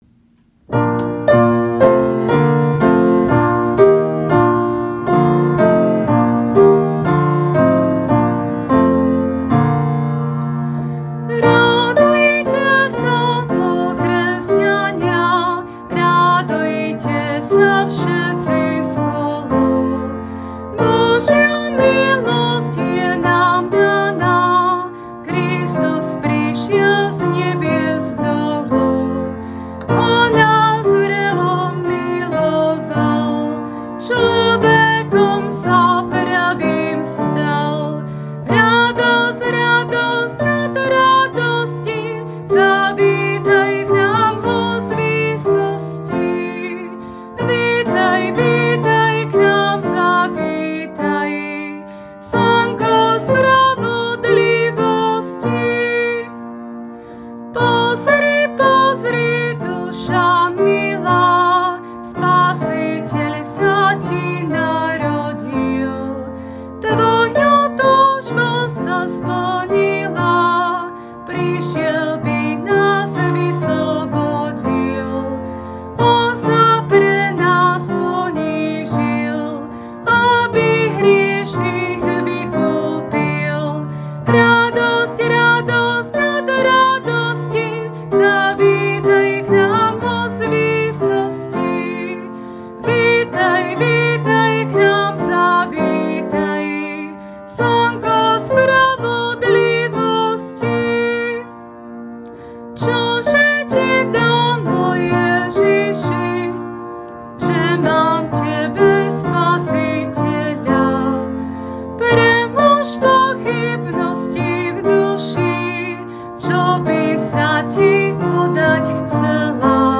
6, Biblické zamyslenie  – Izaiáš 43, 19 a Sk 7, 59-60